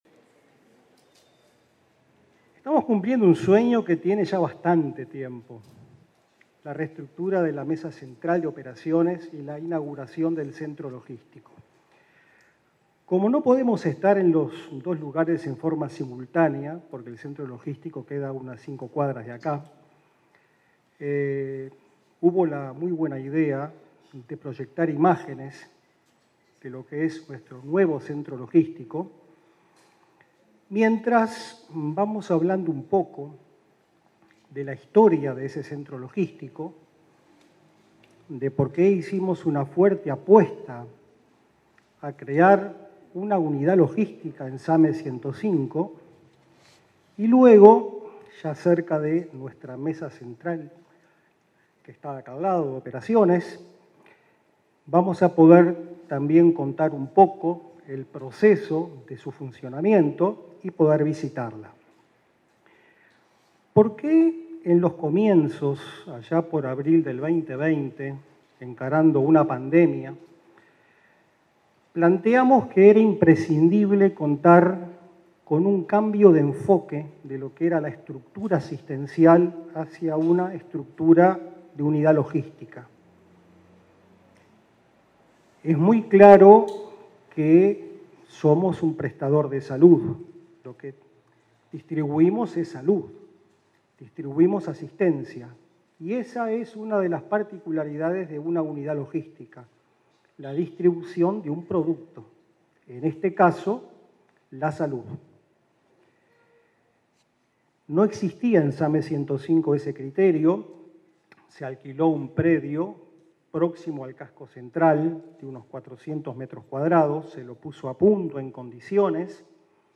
Palabras de autoridades en inauguración de ASSE
Palabras de autoridades en inauguración de ASSE 28/02/2023 Compartir Facebook X Copiar enlace WhatsApp LinkedIn La Administración de los Servicios de Salud del Estado (ASSE) inauguró el lunes 27 un centro de atención telefónica y logístico del Sistema de Atención Médica de Emergencia (SAME 105). El director del SAME, José Antonio Rodríguez; el presidente del prestador público, Leonardo Cipriani, y los ministros de Defensa, Javier García, y Salud Pública, Daniel Salinas, destacaron la importancia de este nuevo servicio.